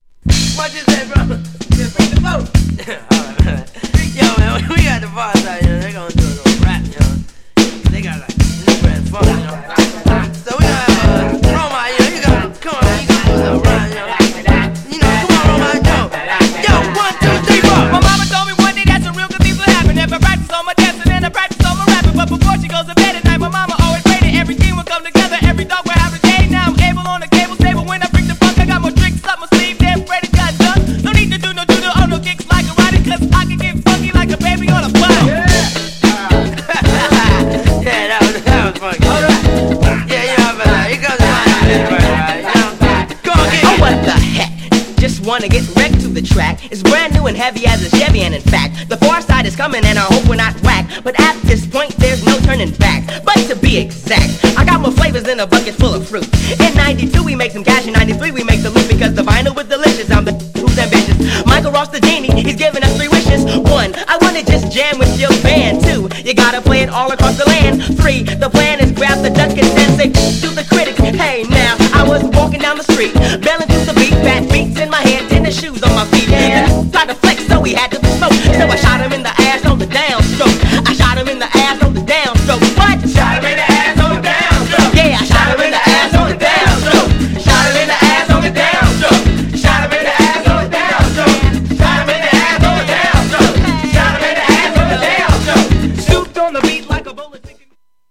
GENRE R&B
BPM 91〜95BPM